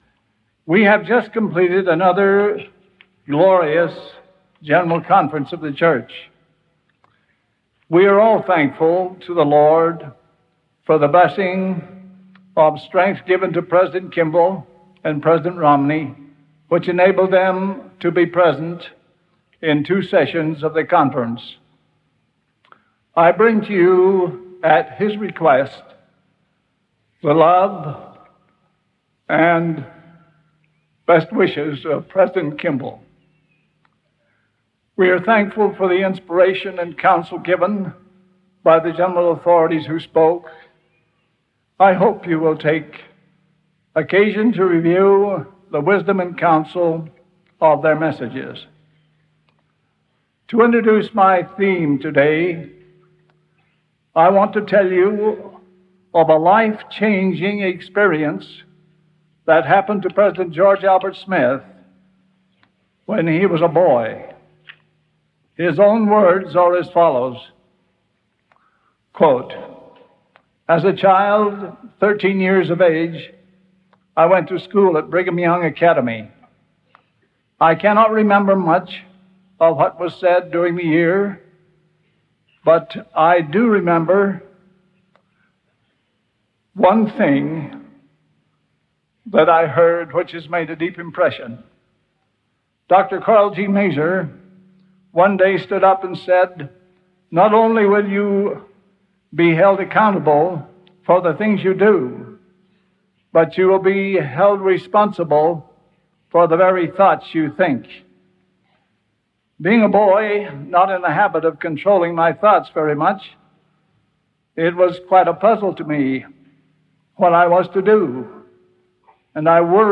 Audio recording of Think on Christ by Ezra Taft Benson
of the Quorum of the Twelve Apostles